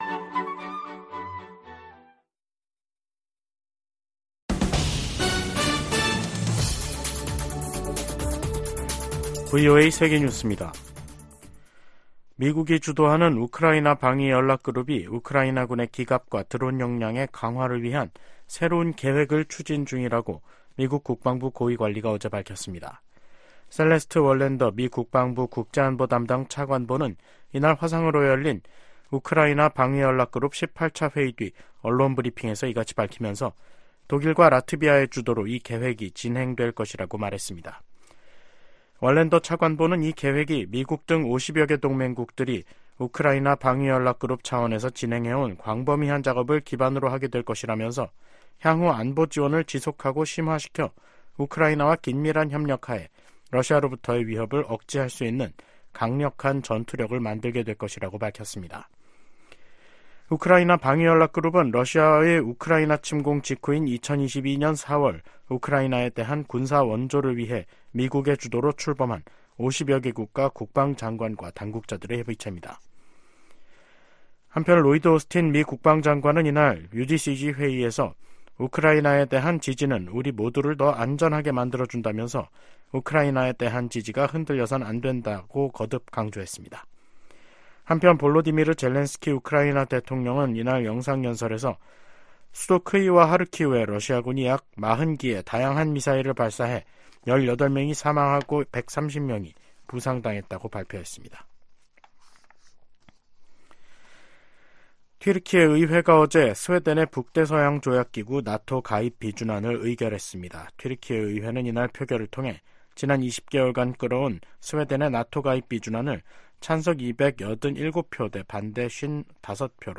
VOA 한국어 간판 뉴스 프로그램 '뉴스 투데이', 2024년 1월 24일 2부 방송입니다. 북한이 서해상으로 순항미사일 여러 발을 발사했습니다.